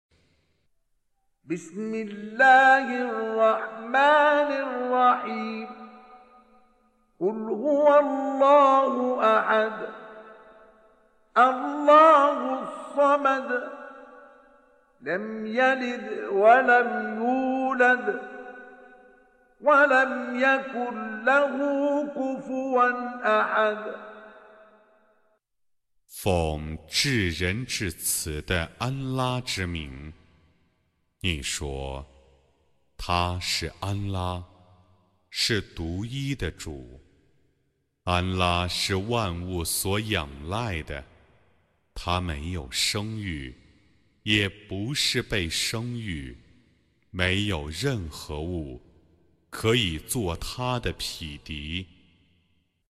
Reciting Mutarjamah Translation Audio for 112. Surah Al-Ikhl鈙 or At-Tauh頳 سورة الإخلاص N.B *Surah Includes Al-Basmalah